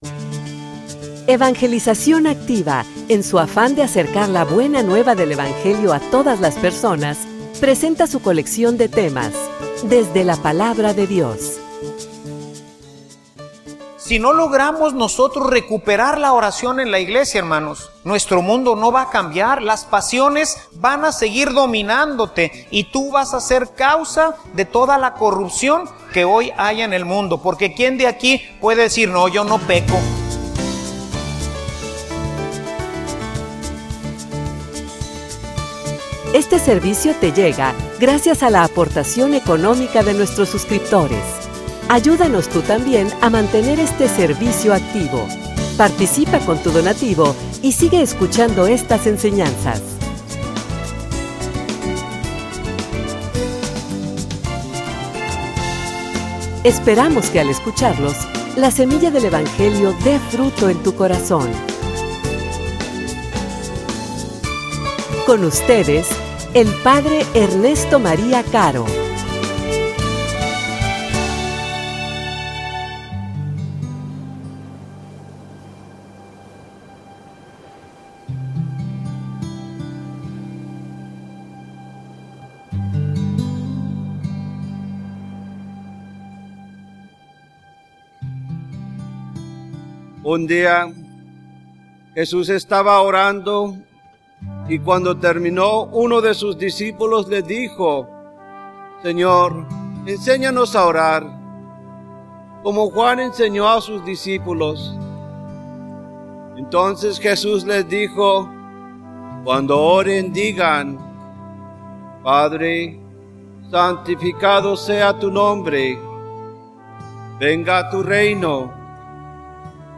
homilia_Persevera.mp3